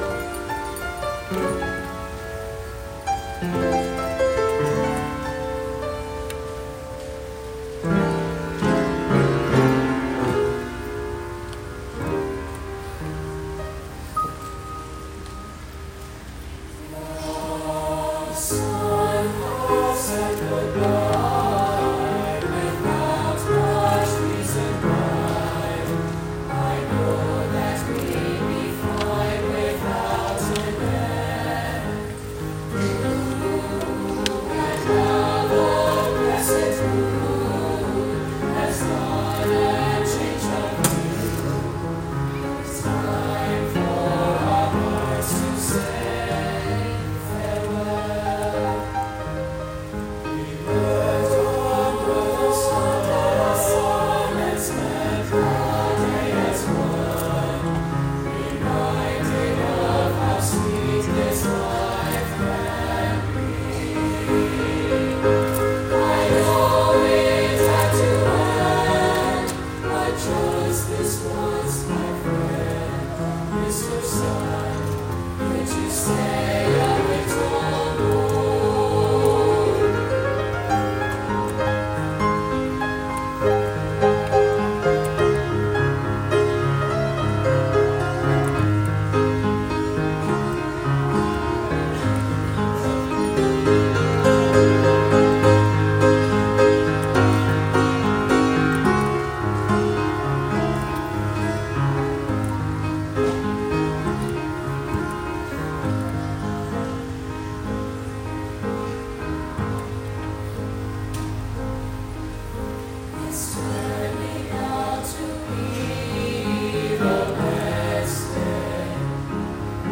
for 3-part mixed choir and piano
A sophisticated and accessible SAB/piano work